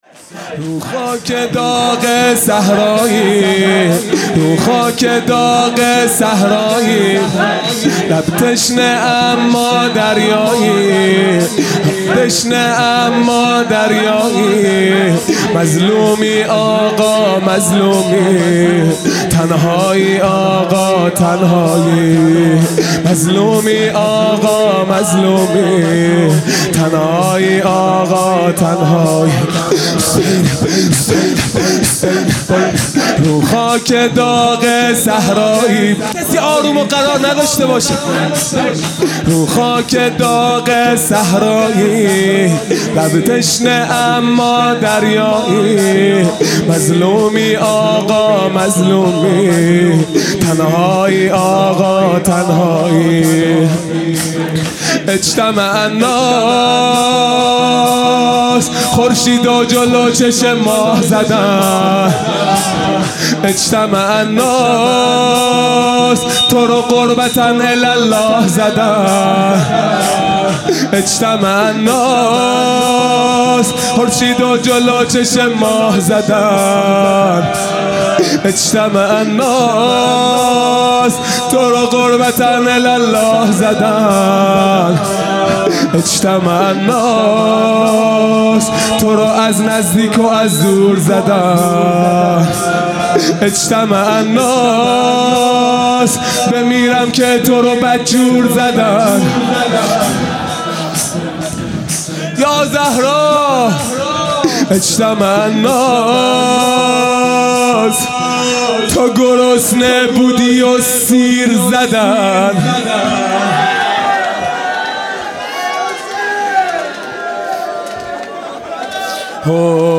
خیمه گاه - هیئت بچه های فاطمه (س) - شور | رو خاک داغ صحرایی
محرم ۱۴۴۱ |‌ شب پنجم